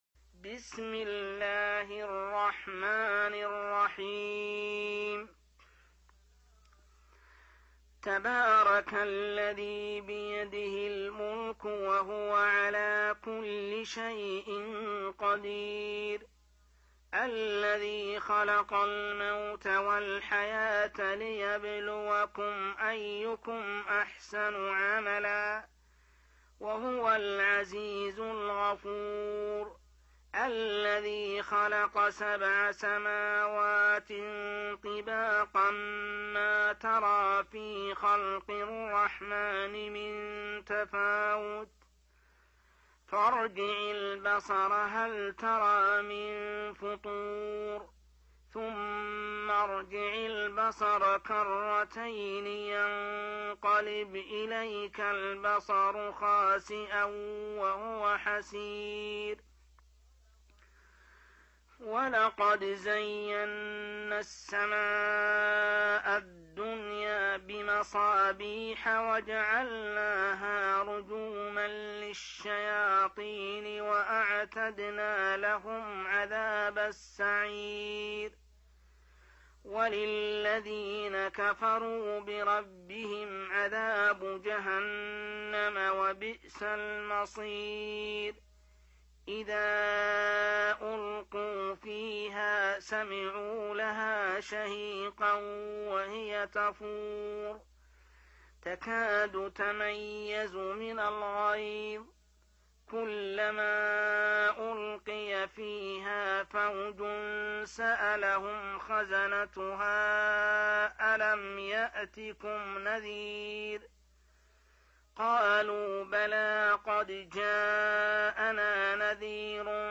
جزء تبارك | Juz' Tabarak > المصحف المرتل للشيخ محمد السبيل > المصحف - تلاوات الحرمين